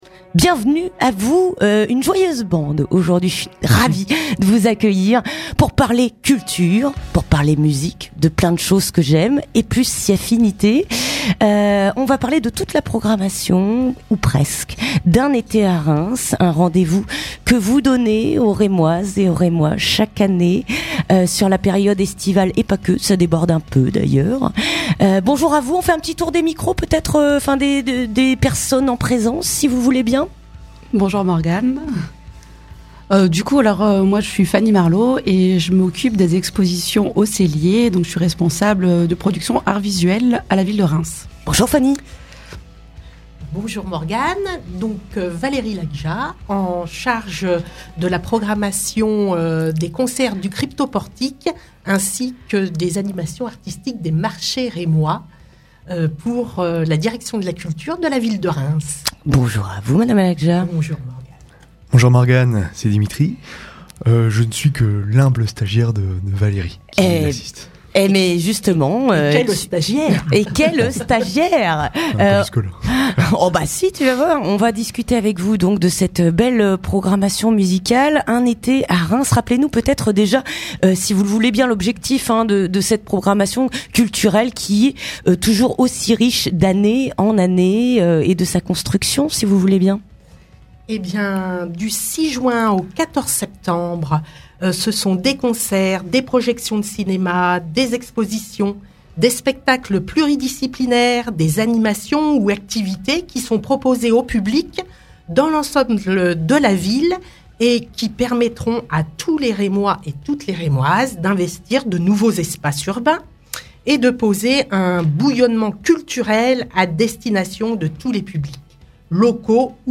Interview de présentation de la saison culturelle